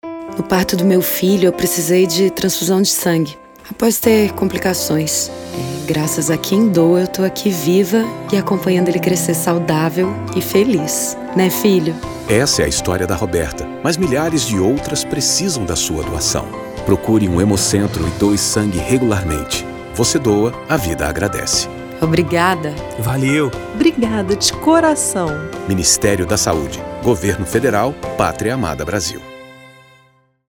Spot - Receptora .mp3